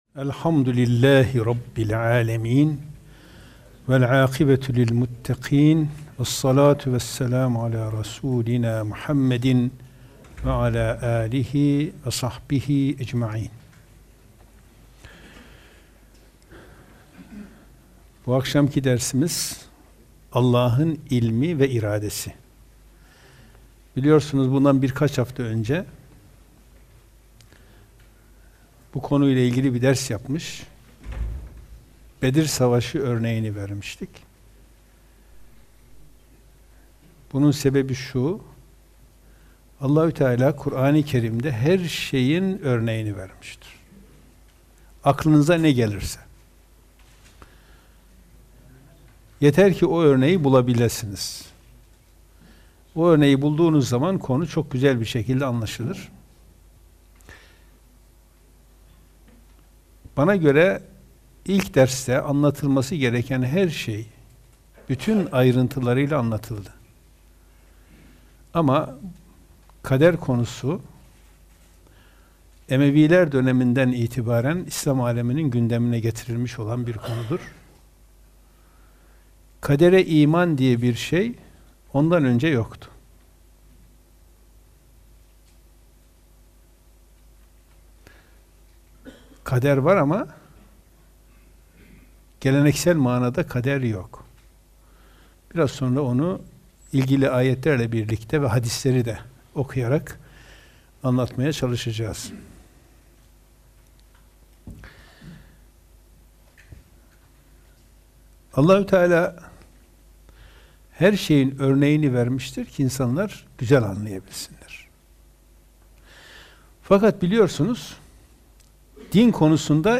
Gösterim: 4.517 görüntülenme Kur'an Sohbetleri Etiketleri: allahın bilgisi > allahın ilmi ve iradesi > kader > kuran sohbetleri Bu akşamki dersimiz Allah’ın ilmi ve iradesi.